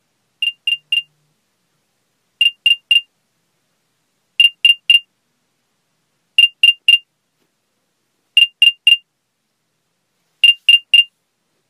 Звук беспроводных наушников Apple AirPods Pro 2 и других в mp3 для монтажа
airpods-poisk.mp3